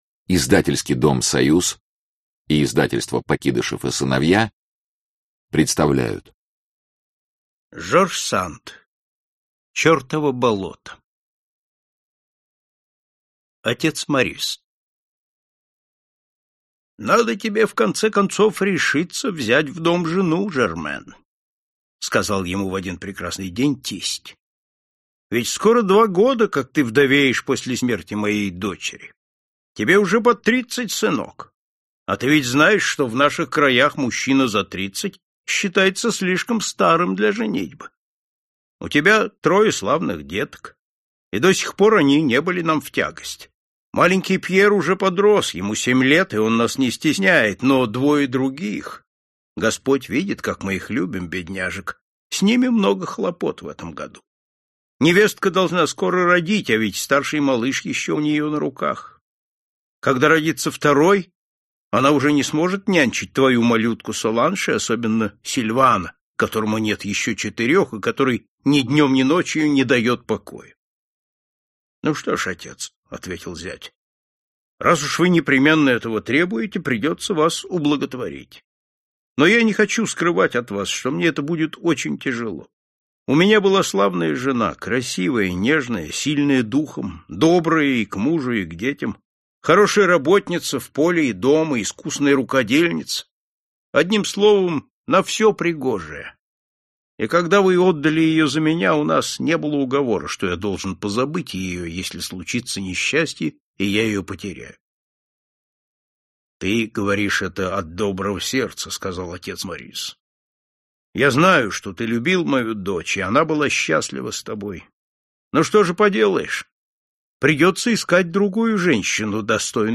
Аудиокнига Чёртово болото | Библиотека аудиокниг